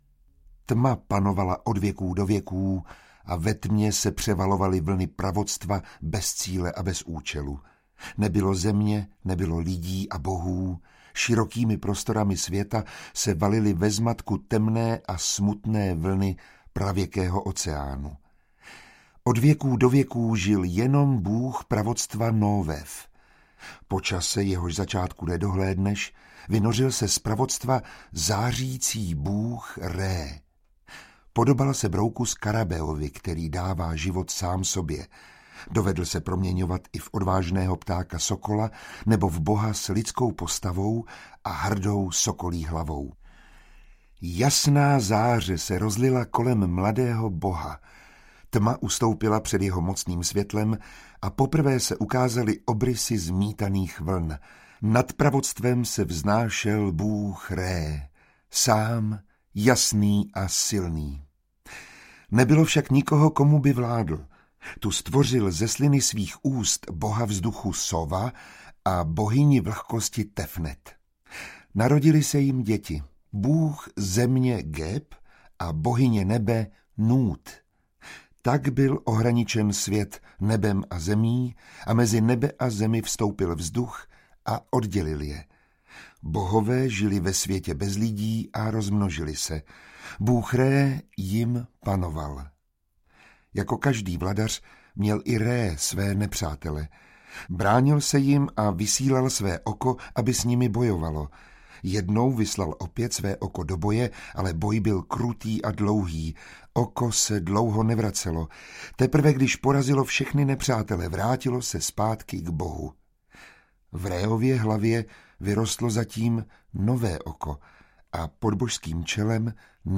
Báje a pověsti starého Egypta a Mezopotámie audiokniha
Ukázka z knihy
• InterpretMiroslav Táborský